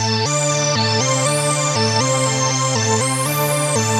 Synth 36.wav